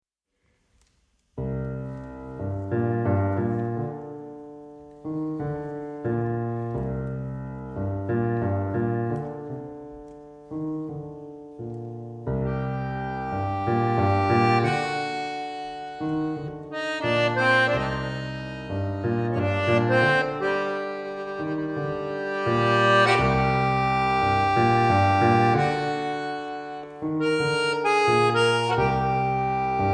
revisited in jazz ways
accordion
piano